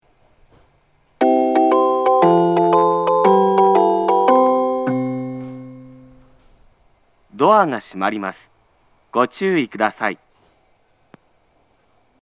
発車メロディー
一度扱えばフルコーラス鳴ります。
混線することが稀にあります。